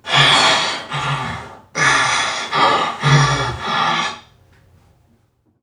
NPC_Creatures_Vocalisations_Robothead [66].wav